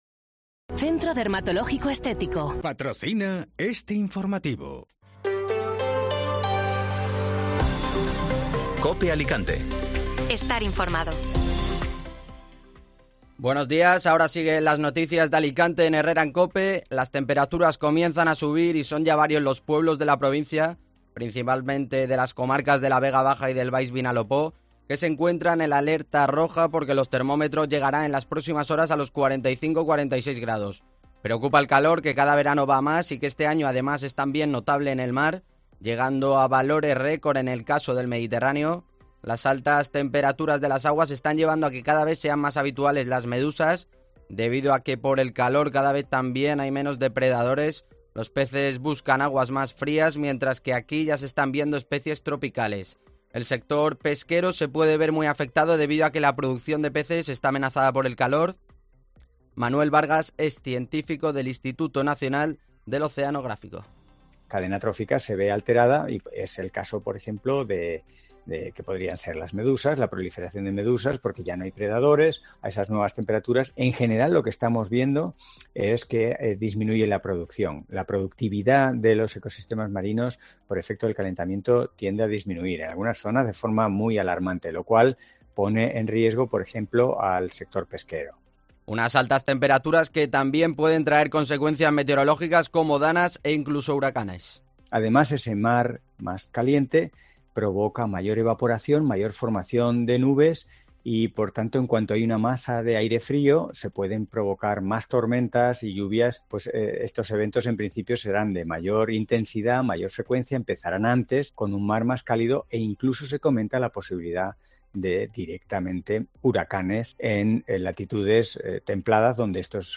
Informativo Matinal (Miércoles 9 de Agosto)